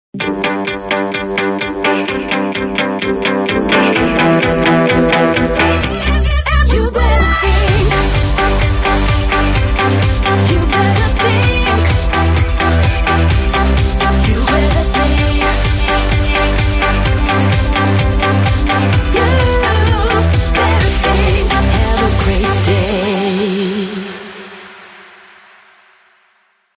Download de ringtone voor iOS